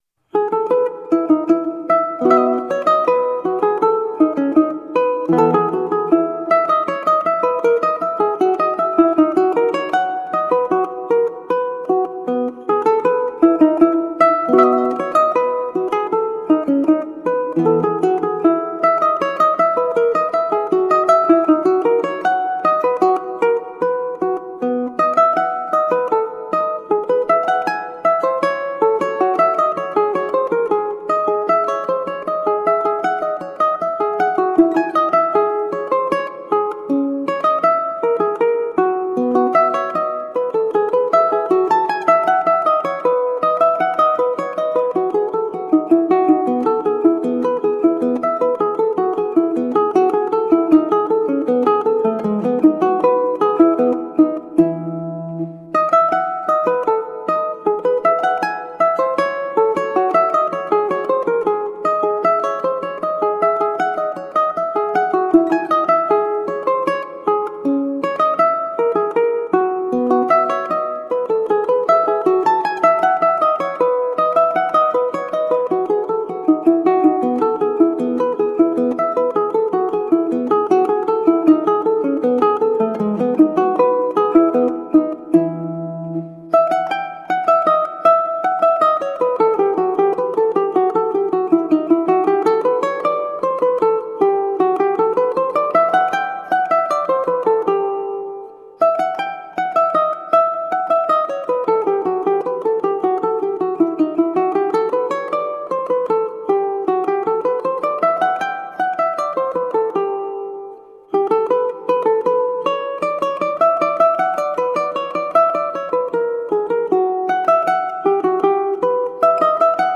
Colorful classical guitar.
Classical, Baroque, Instrumental